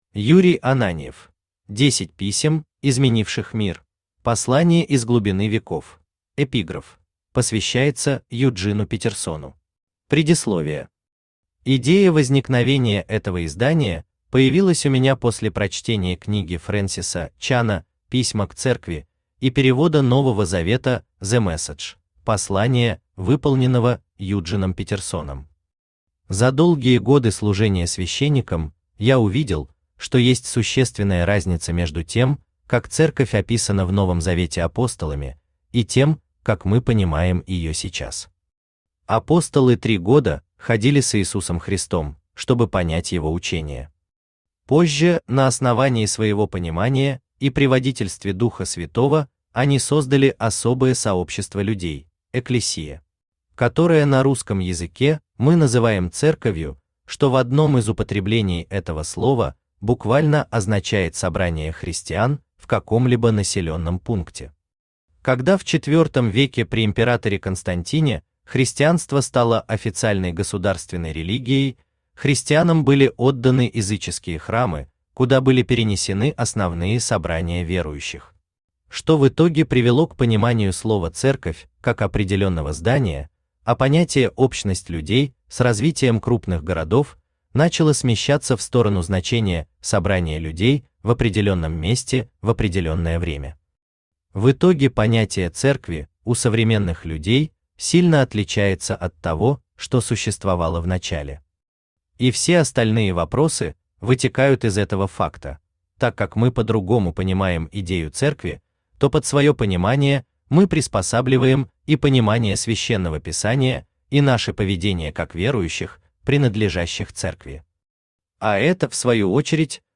Послания из глубины веков Автор Юрий Ананьев Читает аудиокнигу Авточтец ЛитРес.